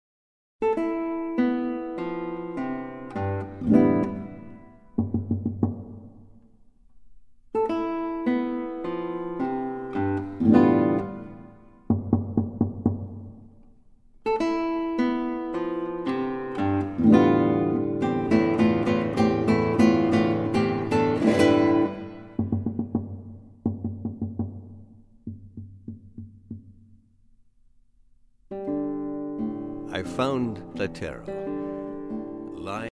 Guitar
Narrator